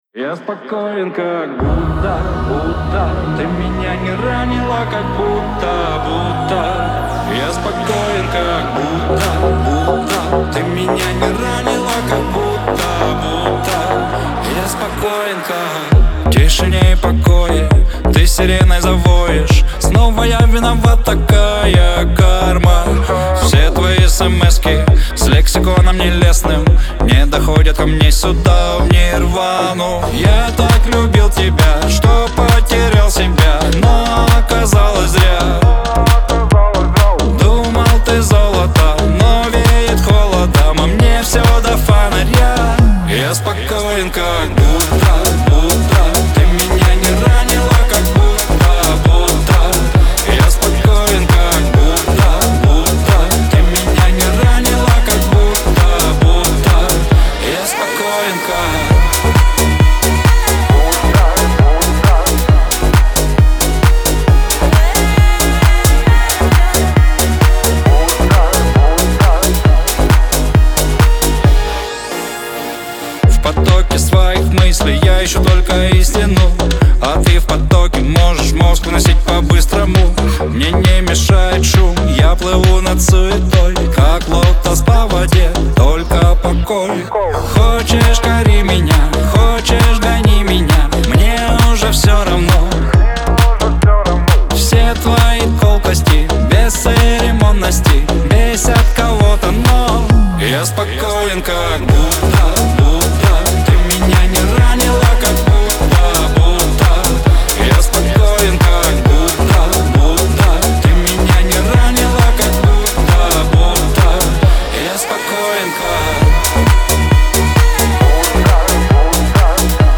грусть , Шансон
Лирика